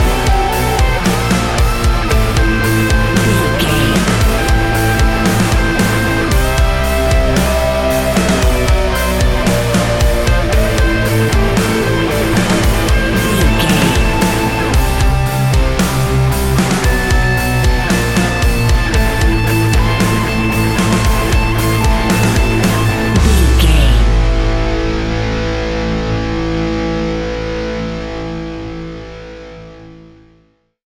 Ionian/Major
A♭
hard rock